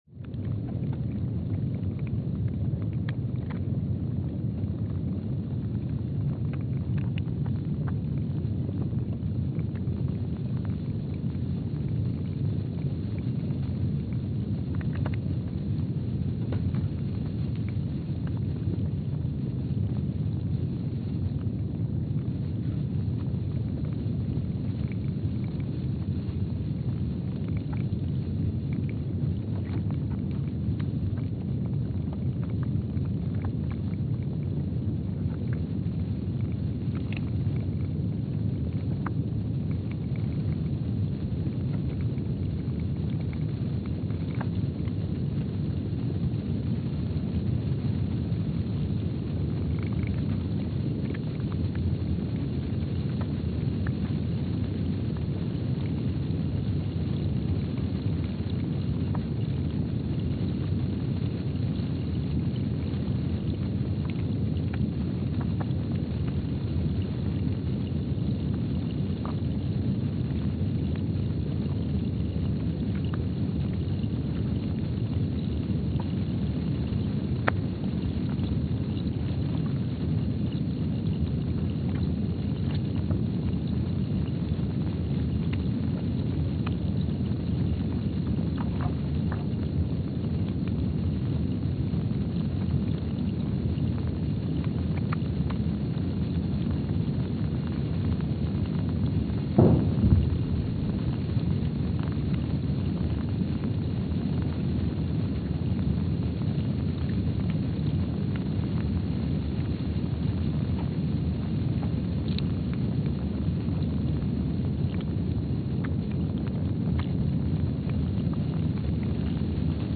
Palmer Station, Antarctica (seismic) archived on January 2, 2025
Sensor : STS-1VBB_w/E300
Speedup : ×500 (transposed up about 9 octaves)
Loop duration (audio) : 05:45 (stereo)
SoX post-processing : highpass -2 90 highpass -2 90